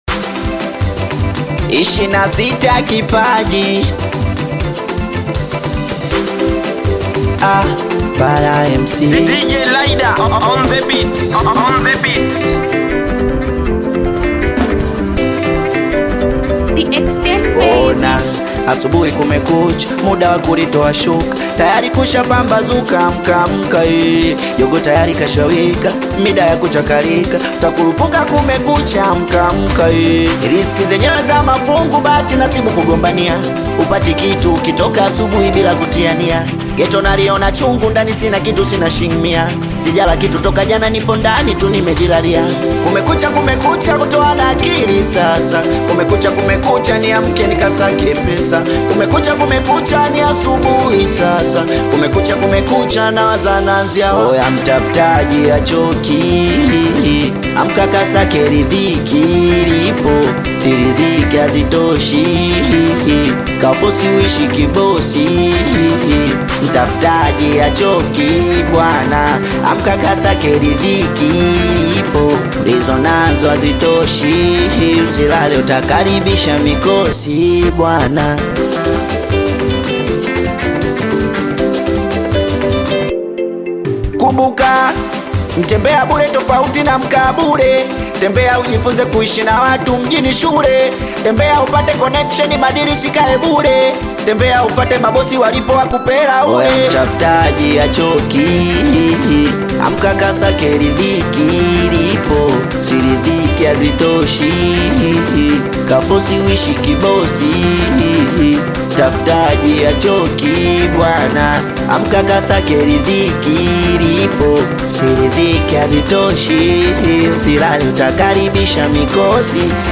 Singeli music track
Tanzanian Bongo Flava singeli artist, singer and songwriter
Singeli song